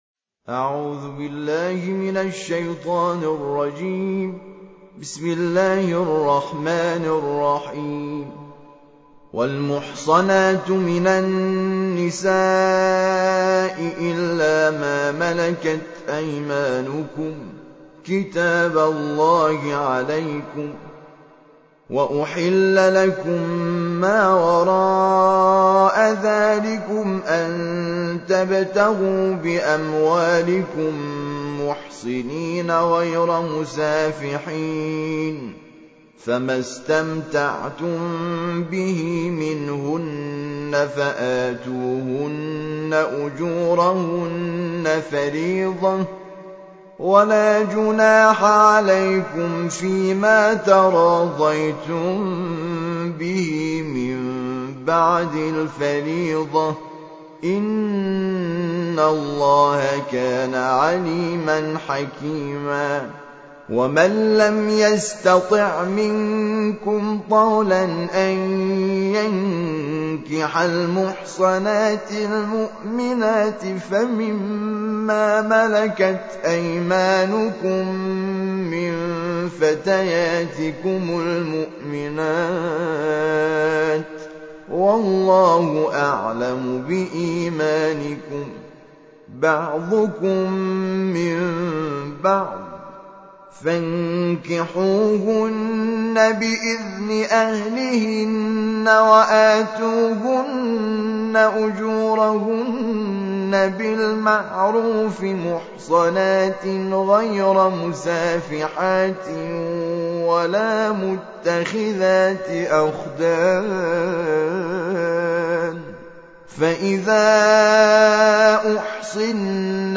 تحميل : الجزء الخامس / القارئ حامد شاكر نجاد / القرآن الكريم / موقع يا حسين